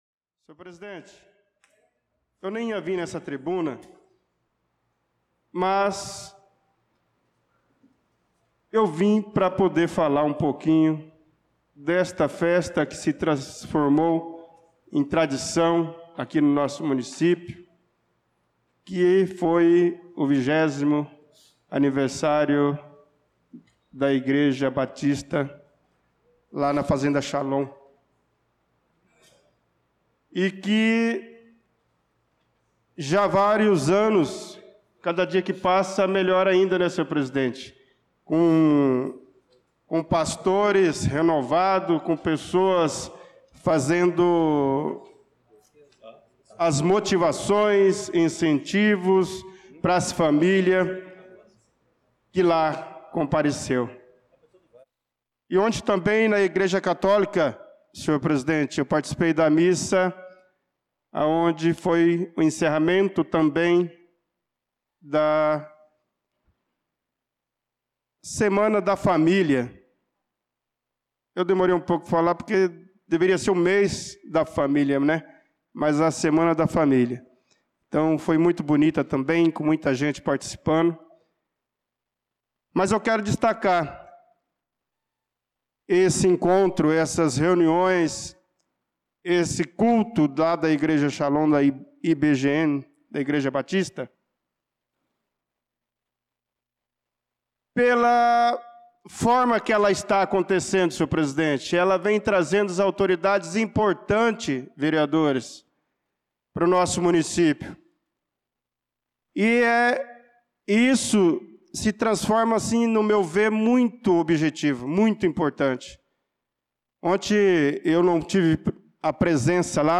Pronunciamento do vereador Bernardo Patrício na Sessão Ordinária do dia 18/08/2025.